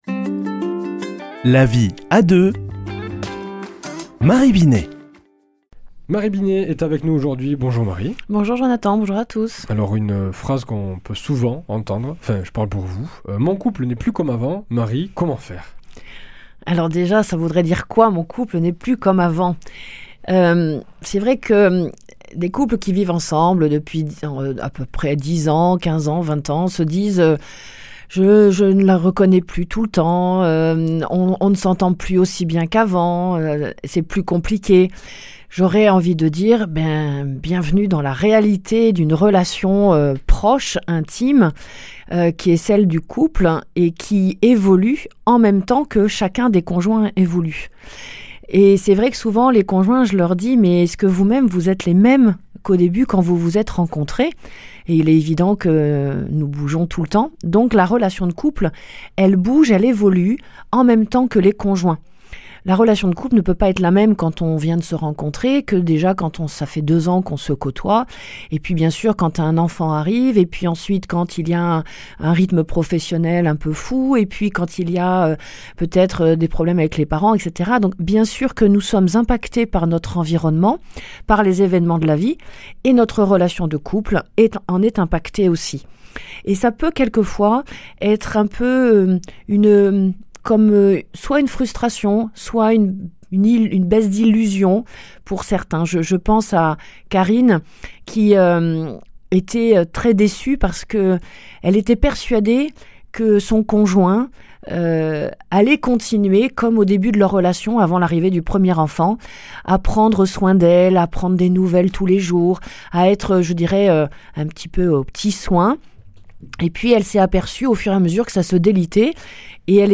mardi 21 janvier 2025 Chronique La vie à deux Durée 4 min
Une émission présentée par